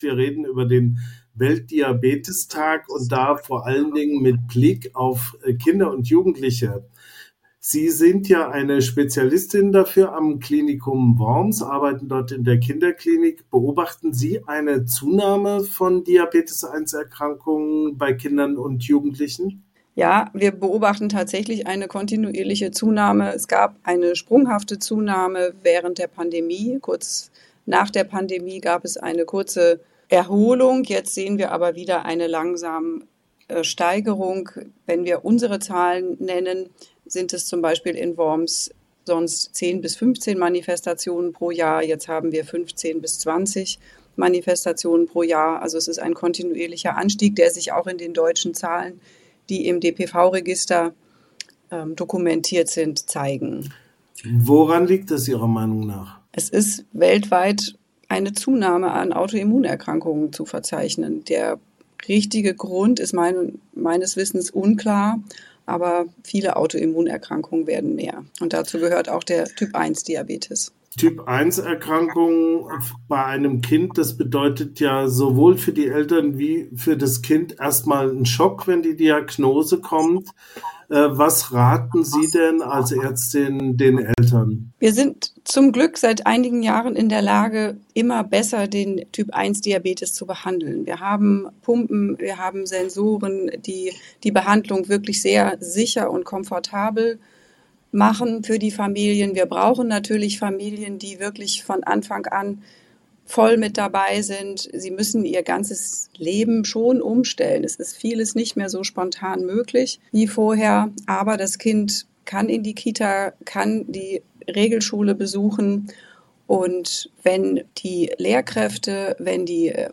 Interview mit Kinderärztin über Diabetes Typ 1: Krankheit kein Hindernis für Sport